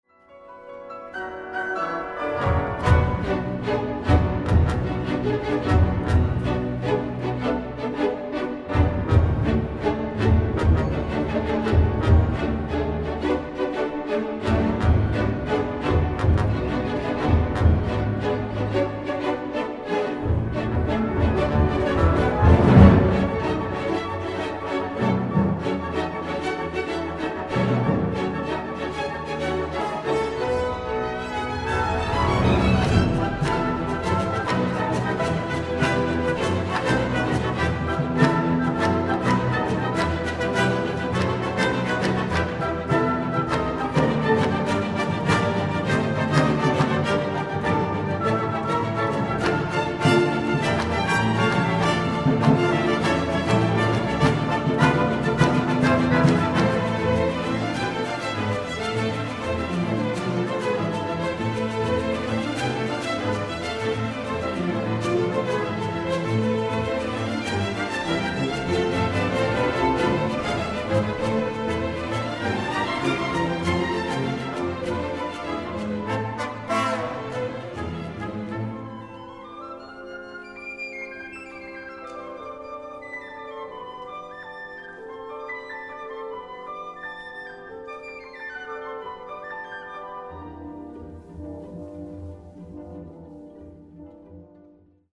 A ritmo de claves y timbales, el autor le dio al género un sonido orquestal que ha trascendido en el tiempo.